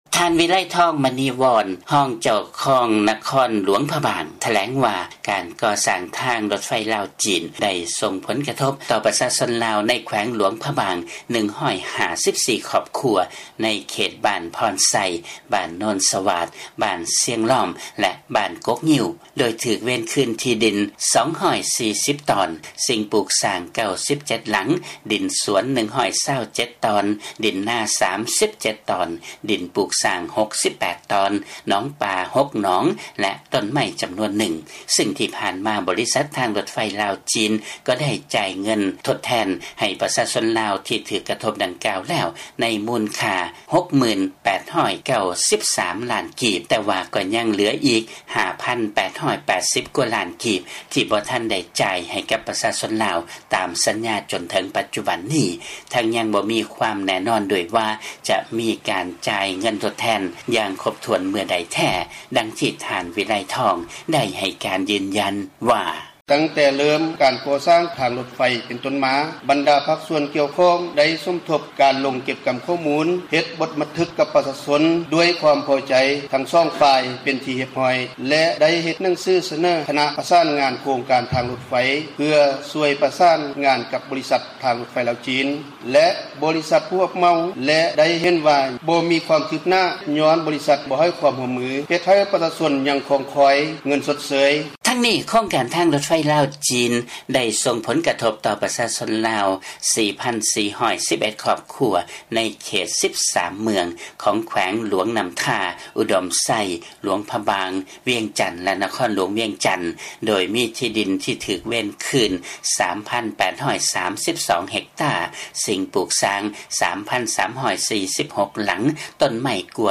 ມີລາຍງານຈາກບາງກອກ.
ເຊີນຟັງລາຍງານ ປະຊາຊົນໃນແຂວງຫຼວງພະບາງ ທີ່ຖືກກະທົບຈາກໂຄງການກໍ່ສ້າງທາງລົດໄຟ ໄດ້ຮັບເງິນຊົດເຊີຍຄ່າທີ່ດິນ ແລະສິ່ງປູກສ້າງ ບໍ່ຄົບຕາມສັນຍາ ຈົນເຖິງປັດຈຸບັນນີ້